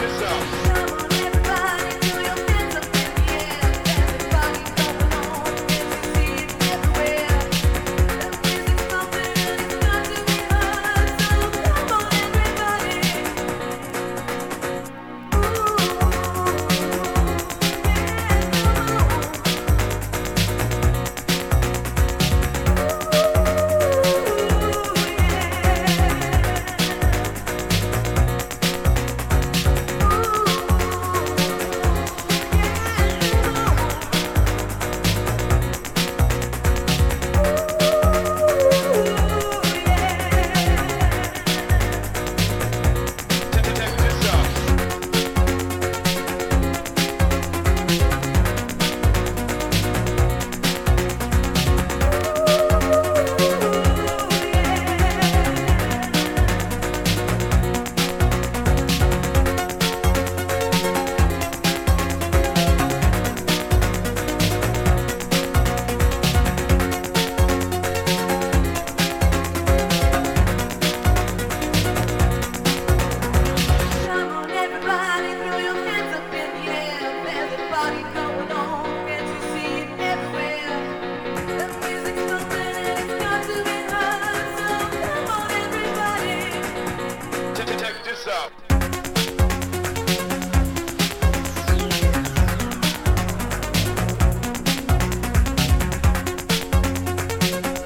UK Garageアプローチのサウンドなのですが、胸キュンBreaks